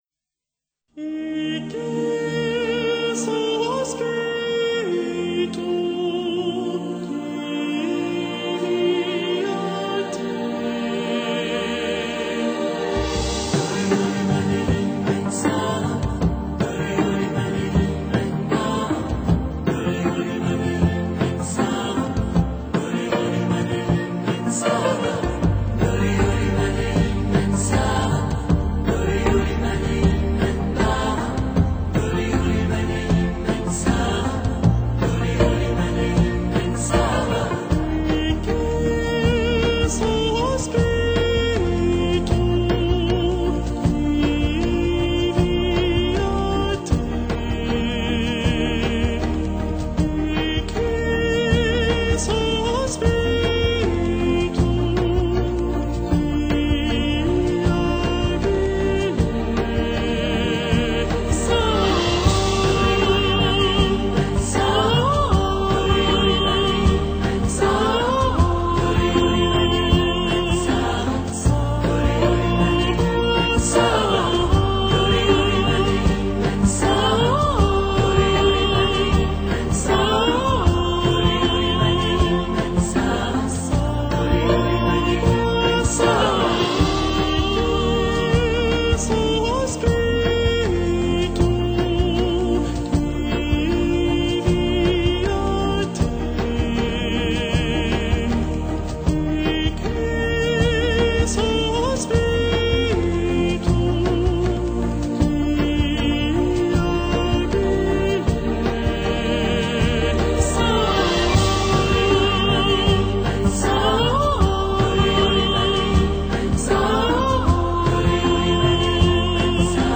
实在太完美了，几乎没有一丝缺陷，无论音质还是唱腔，还是柔软处的转折，还是高亢时的激荡，嗓音都处理得不温不火、游刃有余。
他女高音式的男声，能轻而易举地扣动每名听众的心弦。